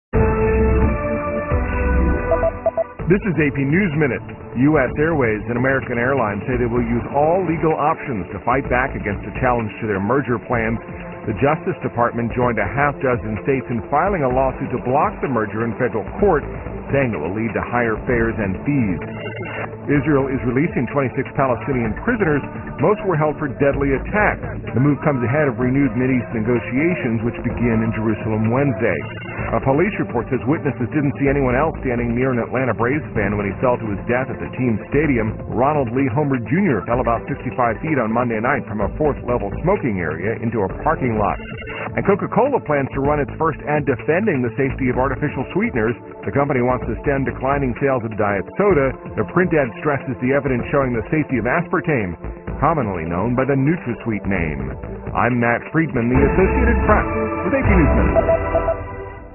在线英语听力室美联社新闻一分钟 AP 2013-08-16的听力文件下载,美联社新闻一分钟2013,英语听力,英语新闻,英语MP3 由美联社编辑的一分钟国际电视新闻，报道每天发生的重大国际事件。电视新闻片长一分钟，一般包括五个小段，简明扼要，语言规范，便于大家快速了解世界大事。